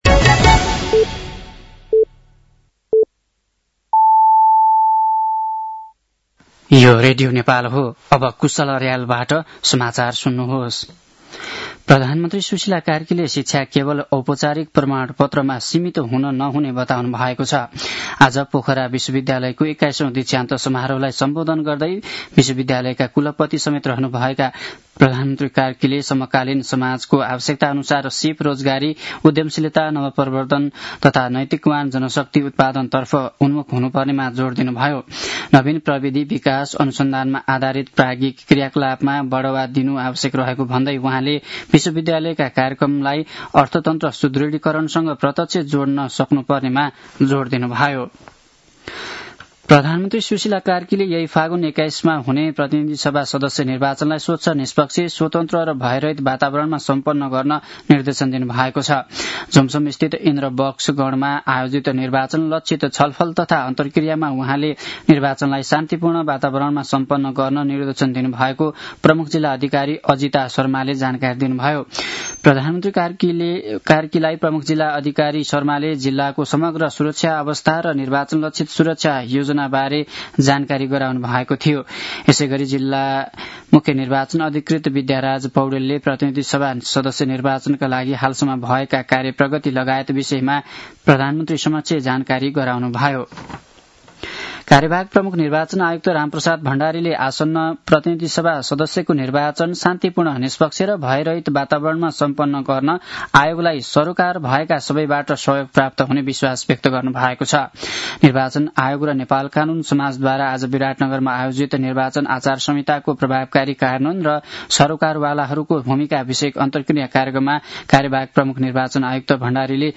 साँझ ५ बजेको नेपाली समाचार : ८ फागुन , २०८२
5-pm-nepali-news-11-08.mp3